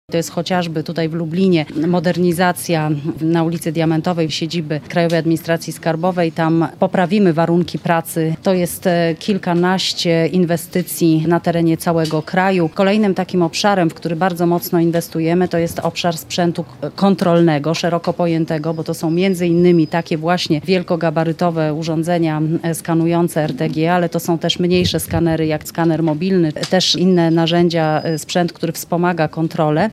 Innowacyjny skaner pojazdów na przejściu granicznym w Terespolu – Na ten rok i kolejny zaplanowaliśmy środki związane z modernizacją naszych budynków, w których KAS realizuje swoje zadania – mówi sekretarz stanu, szefowa Krajowej Administracji Skarbowej Magdalena Rzeczkowska.